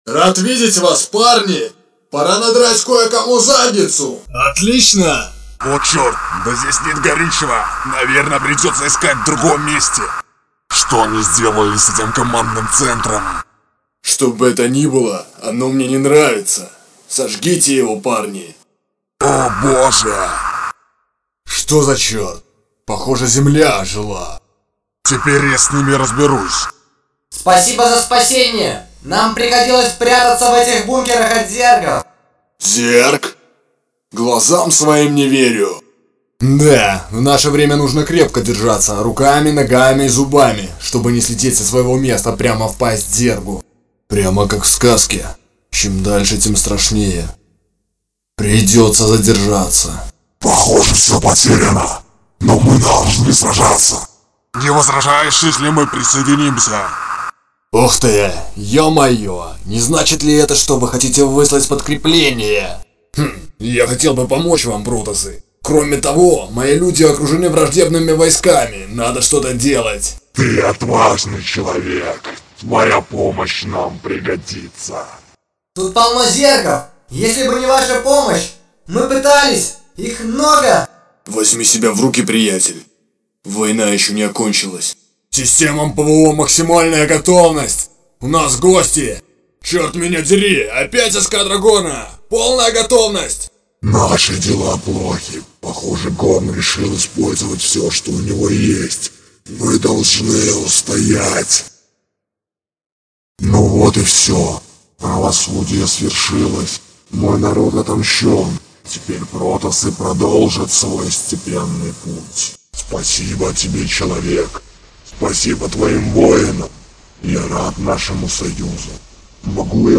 Озвучка игры
Выборка из моей озвучки игровой модификации StarCraft